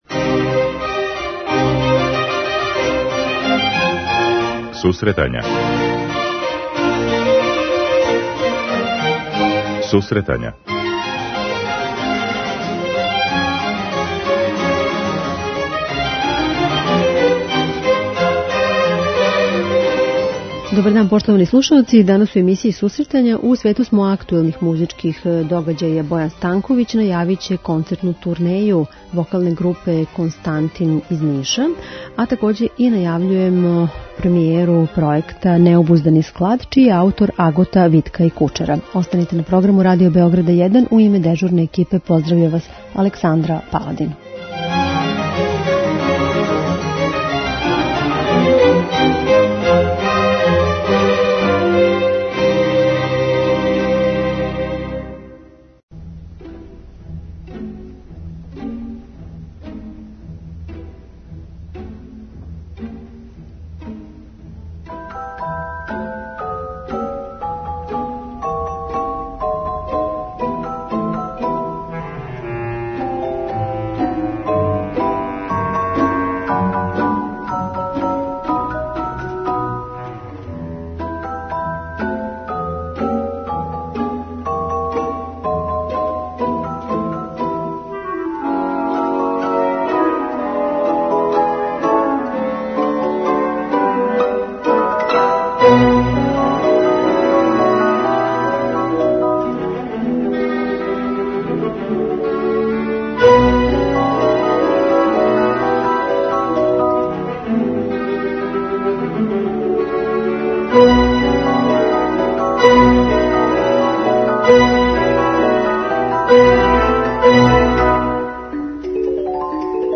преузми : 10.67 MB Сусретања Autor: Музичка редакција Емисија за оне који воле уметничку музику.